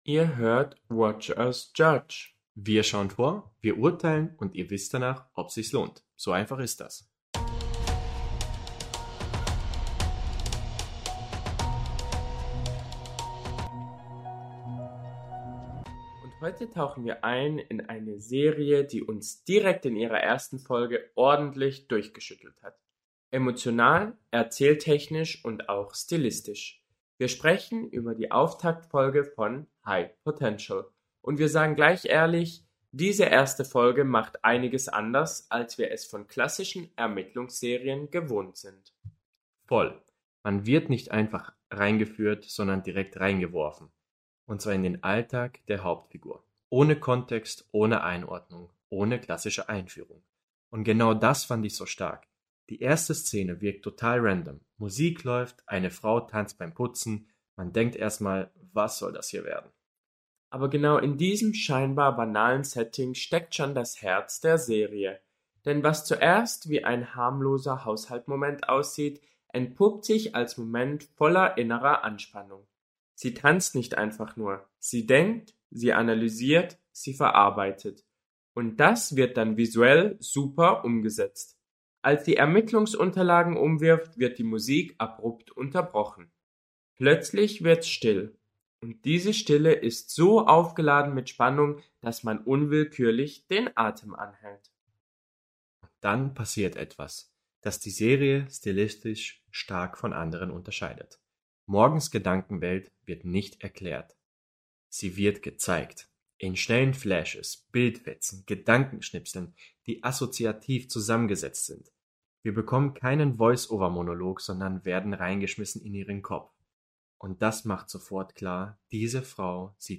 🎙 Ein Paar, eine Watchlist – und mehr Meinung als das Internet braucht.